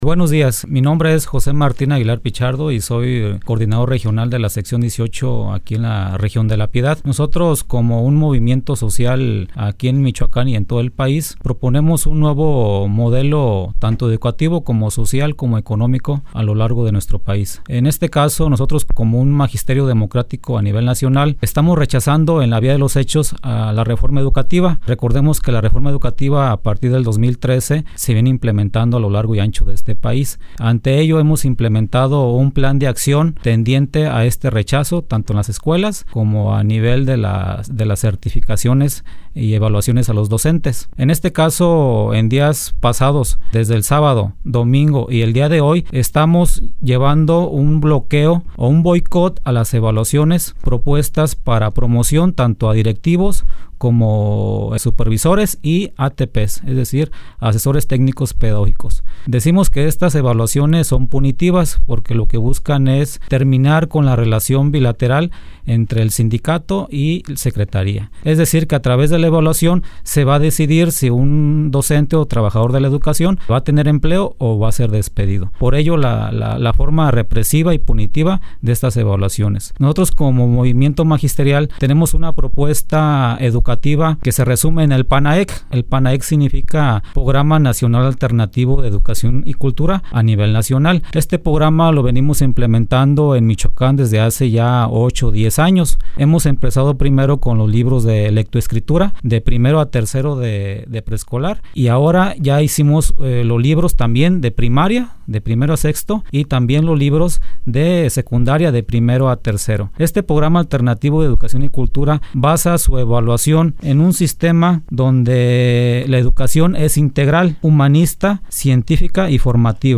MAESTROS DE LA CNTE EN LA RADIO DE LA PIEDAD
SEGURAMENTE USTED SABE QUE SE ESTA REALIZANDO LA REFORMA EDUCATIVA PUES AQUI EN MICHOACAN ESTA ES LA REACCION DE ESTE GRUPO DE MAESTROS, QUE ESTUVIERON EN SUS INSTALACIONES.